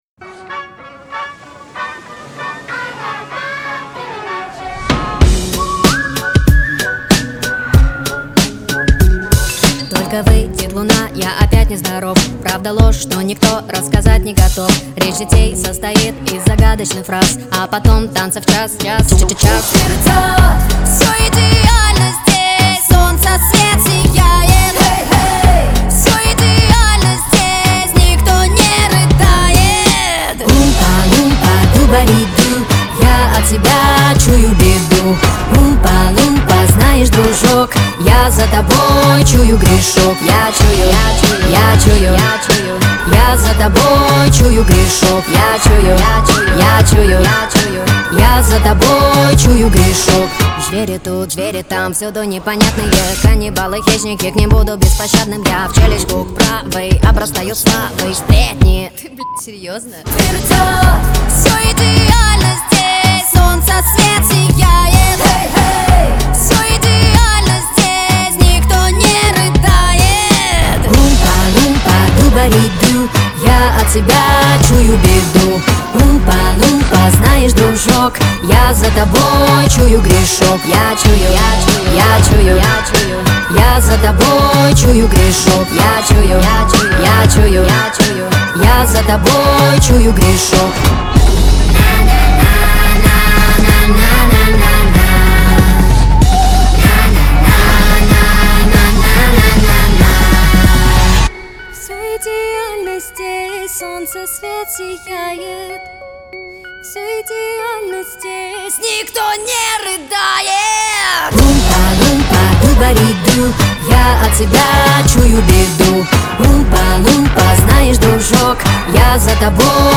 кавер на русском - Russian cover)